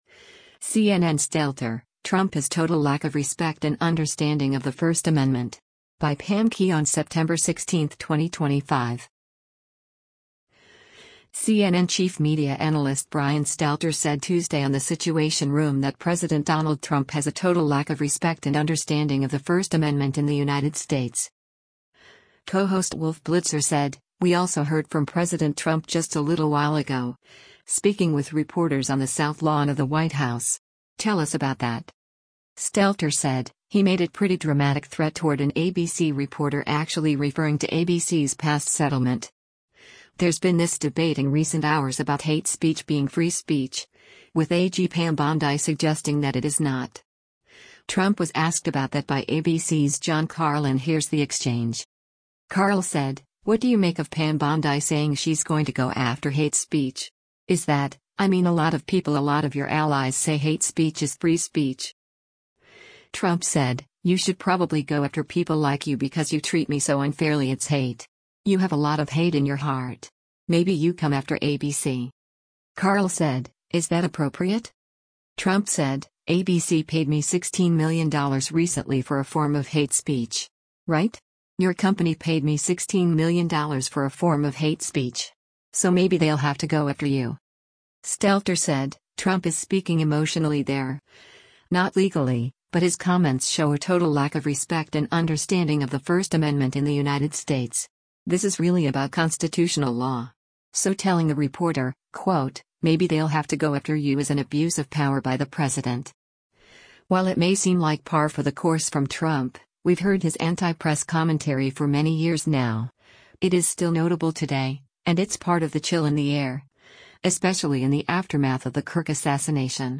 CNN chief media analyst Brian Stelter said Tuesday on “The Situation Room” that President Donald Trump has a “total lack of respect and understanding of the First Amendment in the United States.”